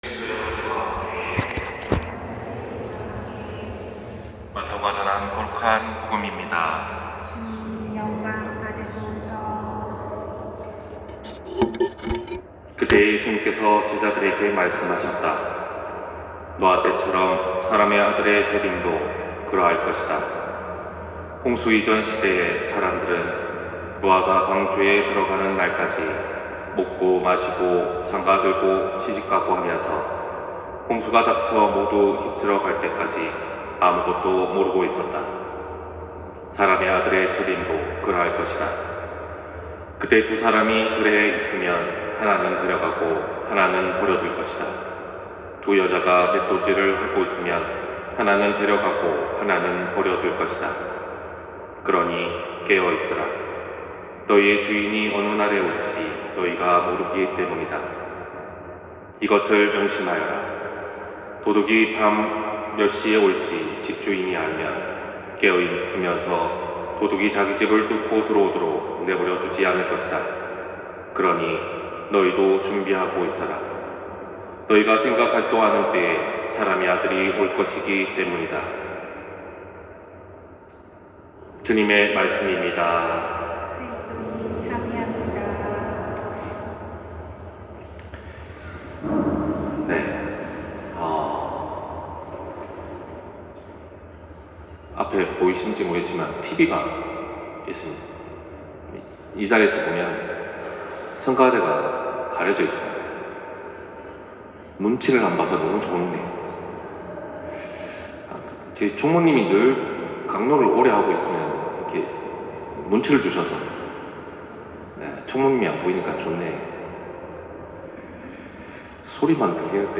251129 신부님강론 말씀